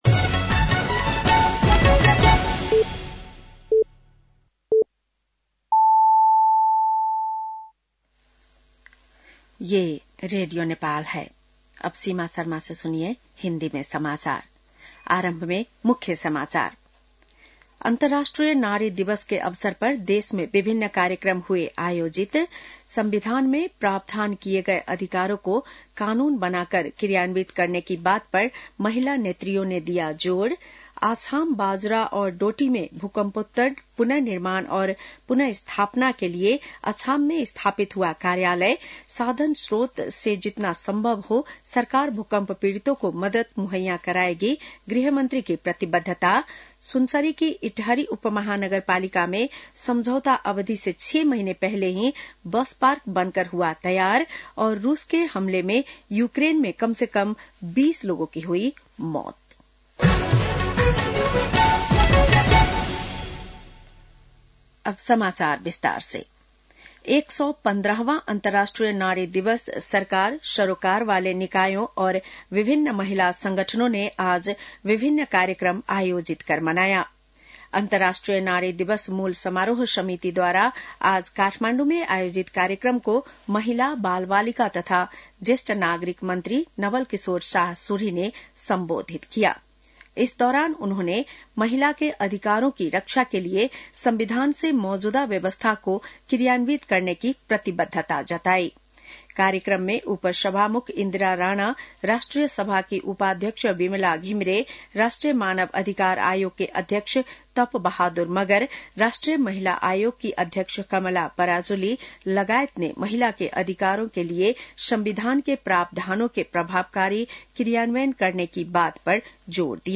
बेलुकी १० बजेको हिन्दी समाचार : २५ फागुन , २०८१
10-pm-news.mp3